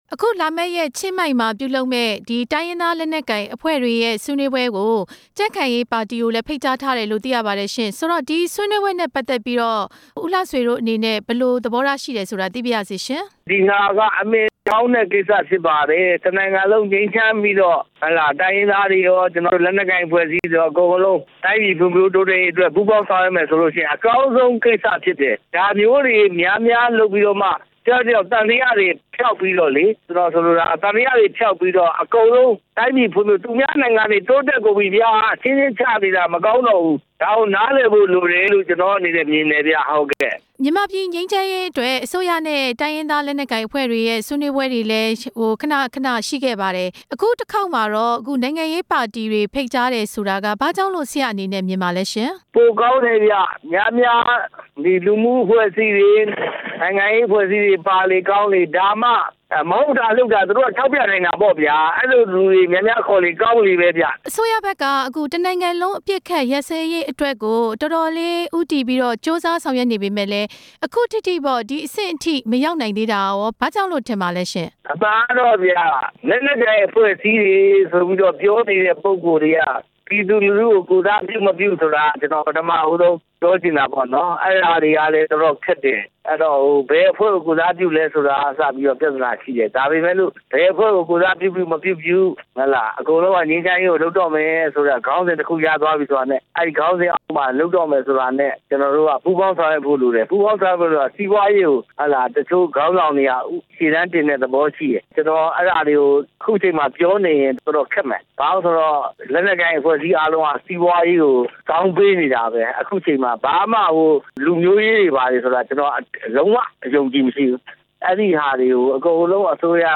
ဦးလှဆွေနဲ့ မေးမြန်းချက်